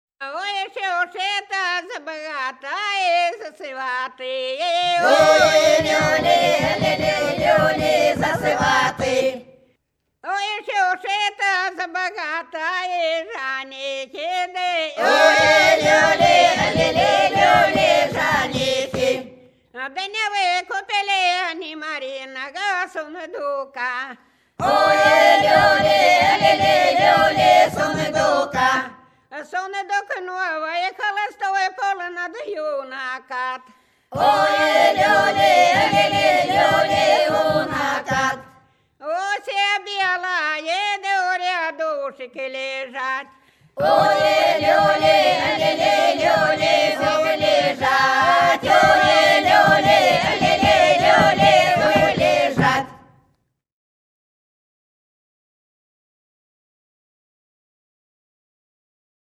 Вдоль по улице пройду (Бутырки Репьёвка) 05. Ой, ще это за богатые за сваты — свадебная (выкуп приданого).
запевала
подголосок